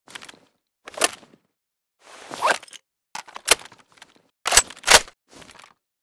vssk_reload_empty.ogg.bak